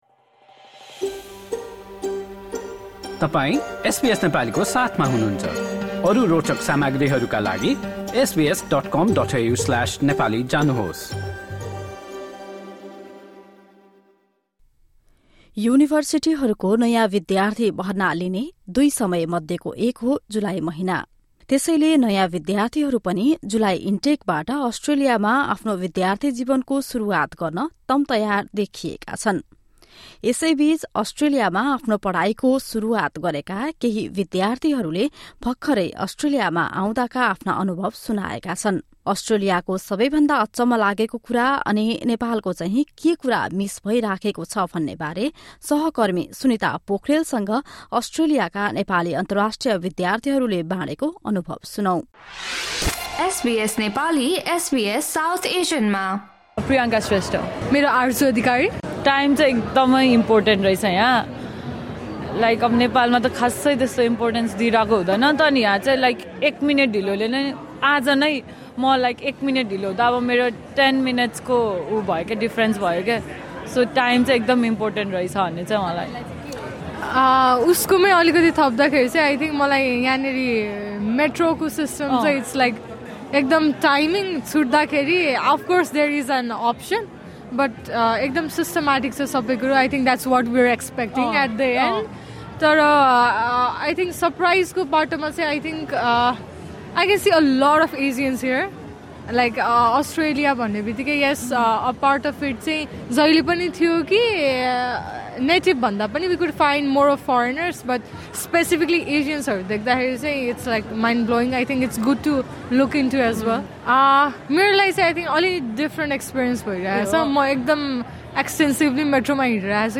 For some, the vegetables here are too expensive, whilst others were amused by the ‘dunny’. Listen to a fun conversation with Nepali students of the Western Sydney University and Macquarie University.